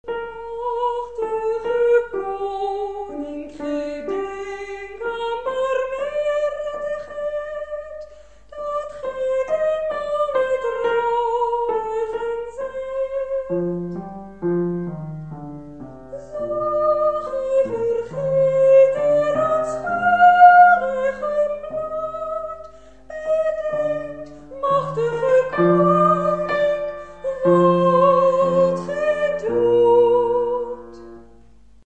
ingezongen in huiselijke kring